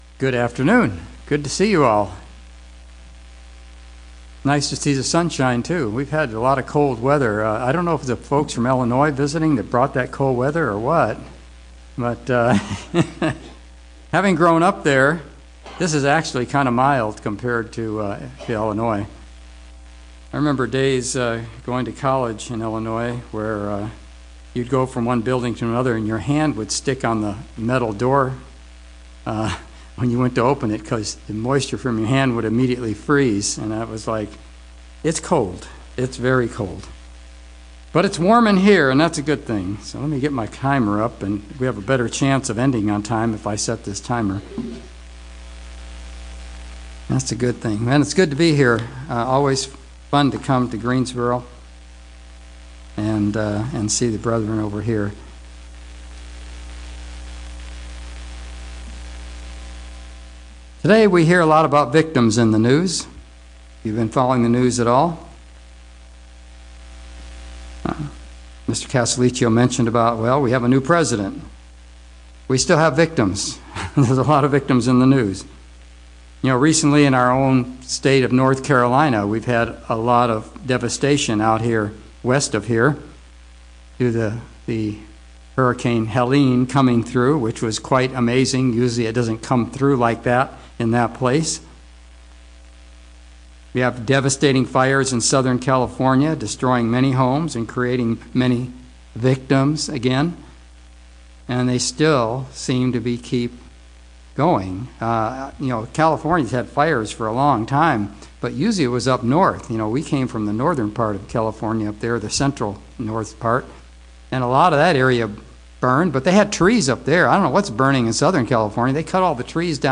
Sermon about how you can go from thinking of yourself as a victim to thinking of yourself as victorious.